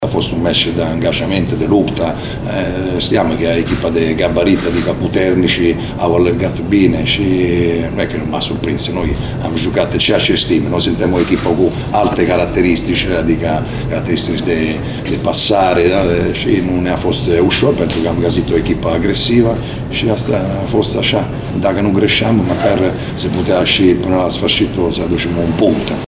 Antrenorul celor de la Sepsi, Cristiano Bergodi, a recunoscut că UTA a fost echipa superioară și a reușit să se impună în acest meci de luptă grație calităților masive pe care le au unii jucători:
Bergodi-echipa-mai-masiva-a-castigat-un-meci-de-lupta.mp3